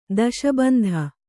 ♪ daśa bandha